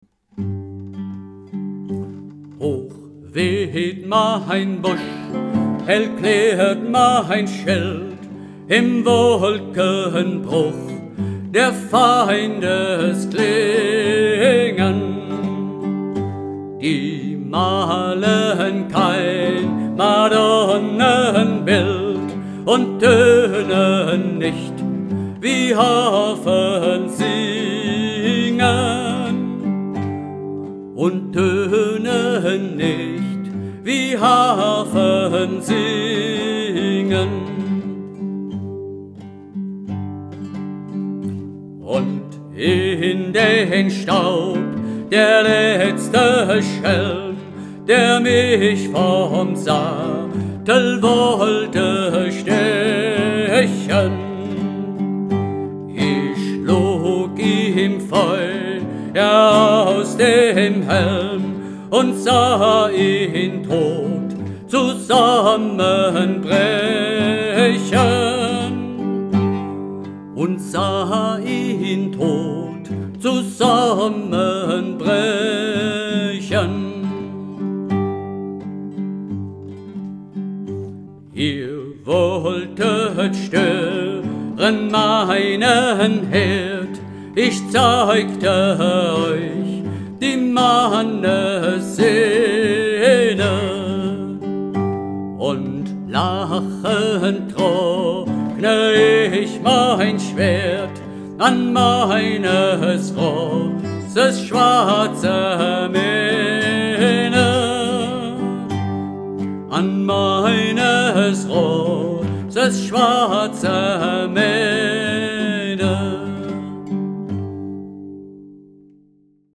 Die Hörprobe stammt von einer Studioaufnahme aus dem Jahr 2012.